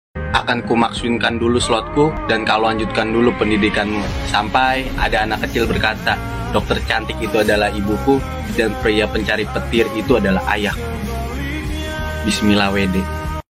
petir.mp3